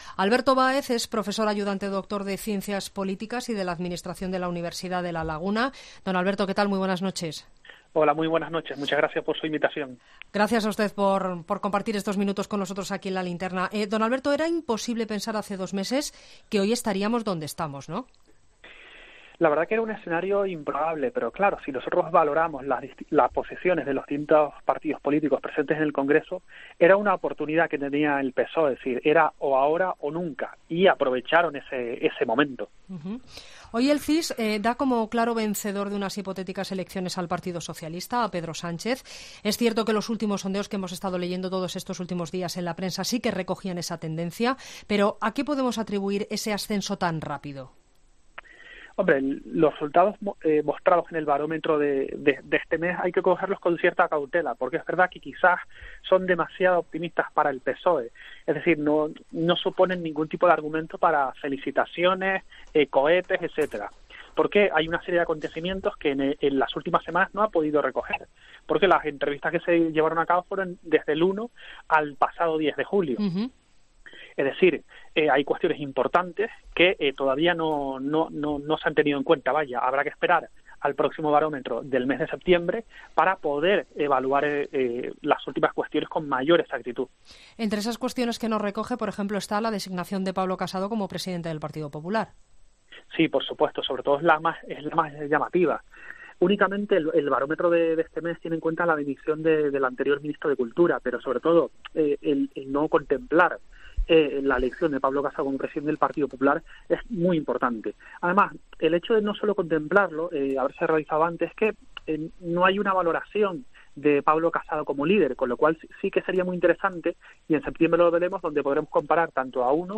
Entrevistas en La Linterna